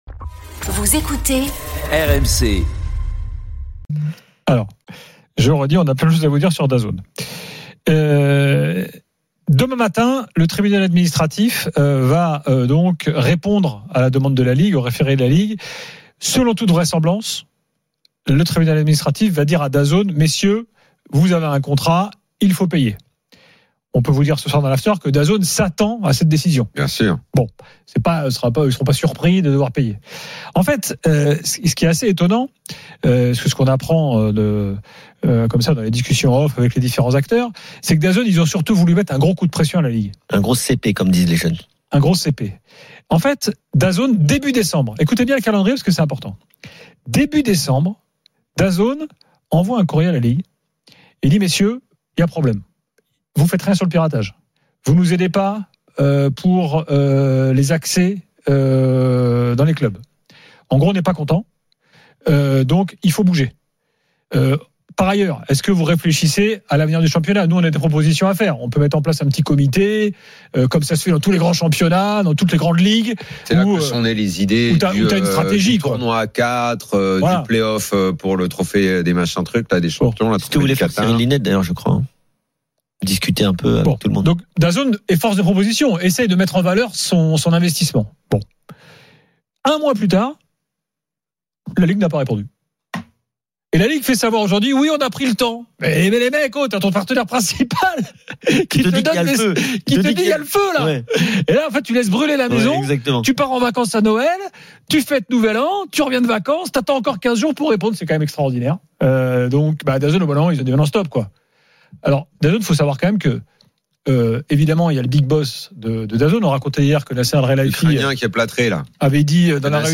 Les rencontres se prolongent tous les soirs avec Gilbert Brisbois, Daniel Riolo et Florent Gautreau avec les réactions des joueurs et entraîneurs, les conférences de presse d’après-match et les débats animés entre supporters, experts de l’After et auditeurs RMC.